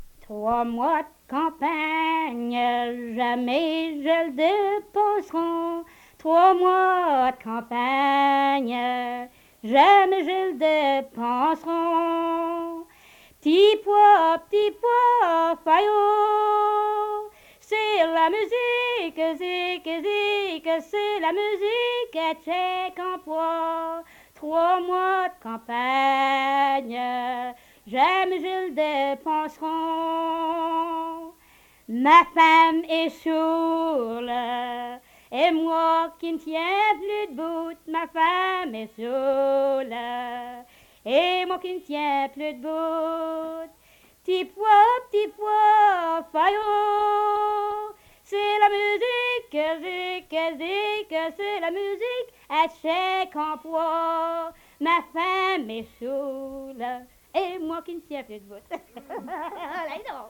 des chansons collectées auprès de chanteurs et de communautés francophones
Emplacement Cap St-Georges